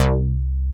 BIG ATK BASS.wav